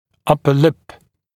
[‘ʌpə lɪp][‘апэ лип]верхняя губа